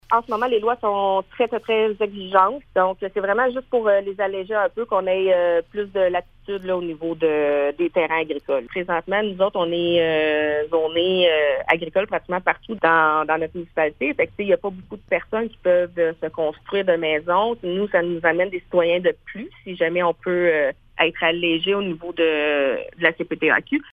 Voici la mairesse de Montcerf-Lytton, Véronique Danis :